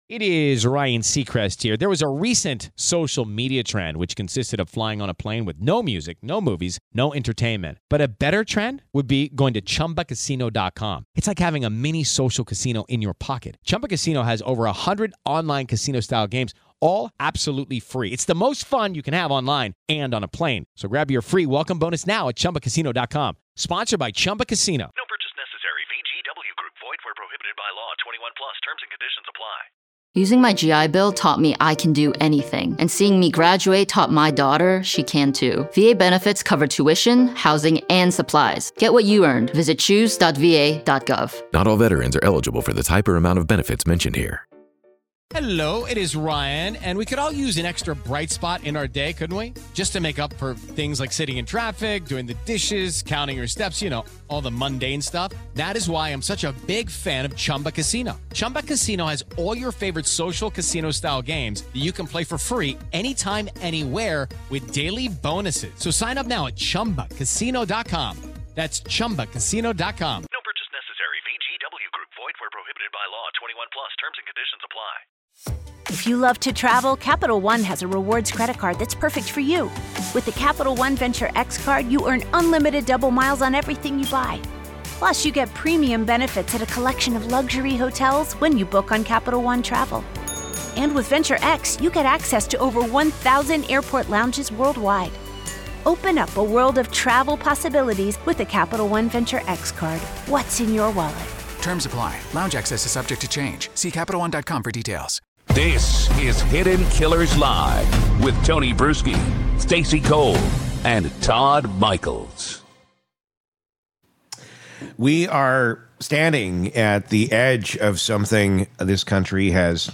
This interview isn’t about politics.